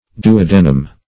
Duodenum \Du`o*de"num\, n. [NL., fr. duodeni twelve each: cf. F.